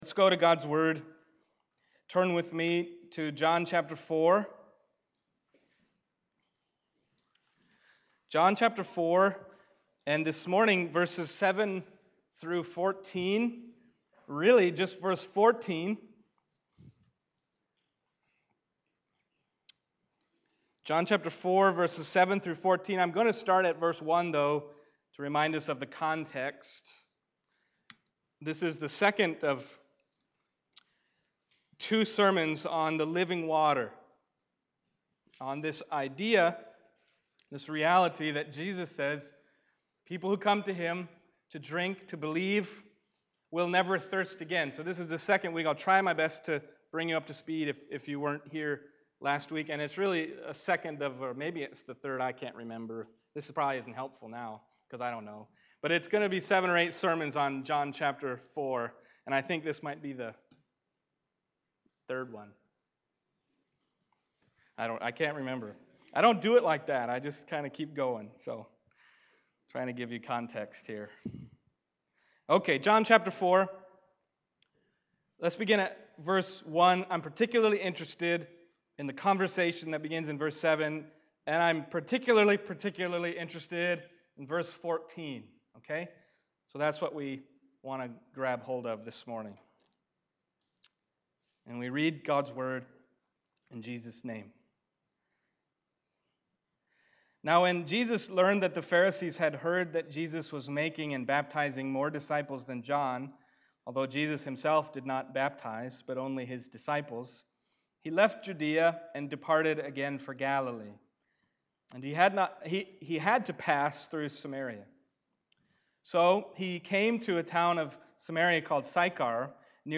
John Passage: John 4:7-14 Service Type: Sunday Morning John 4:7-14 « Never Thirst Again Come